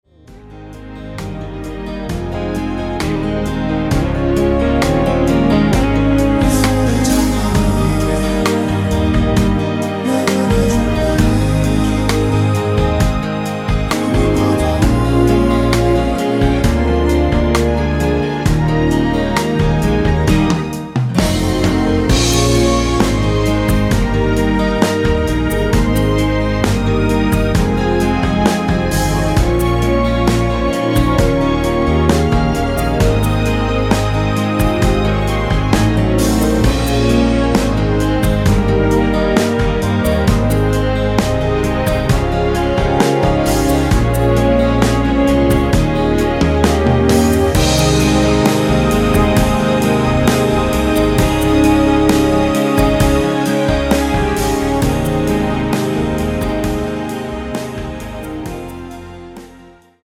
전주 없이 시작 하는곡이라 노래 부르기 쉽게 전주 1마디 편곡 추가되었습니다.(미리듣기참조)
Ab
◈ 곡명 옆 (-1)은 반음 내림, (+1)은 반음 올림 입니다.
앞부분30초, 뒷부분30초씩 편집해서 올려 드리고 있습니다.
중간에 음이 끈어지고 다시 나오는 이유는